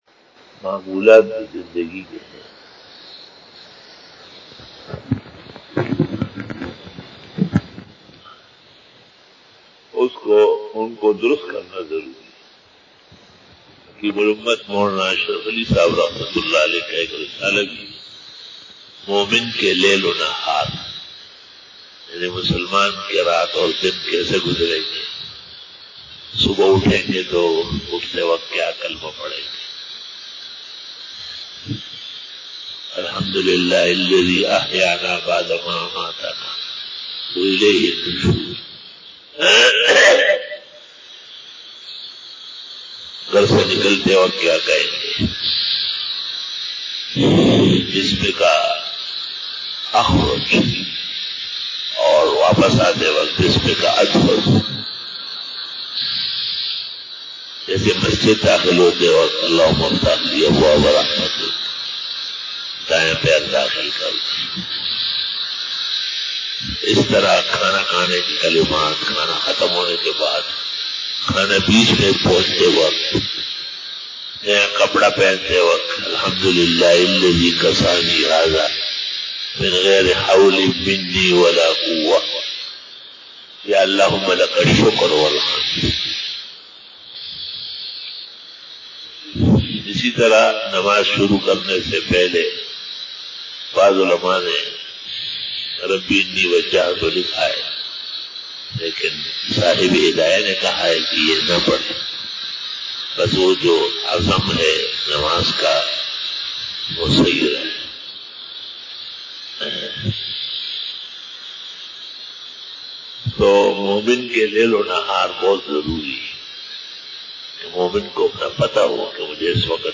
Fajar bayan 17 October 2020 (29 Safar ul Muzaffar 1442HJ) Saturday